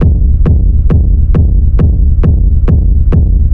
• Techno Sub Kick Black.wav
Techno_Sub_Kick_Black_1_-1-1_Kqd.wav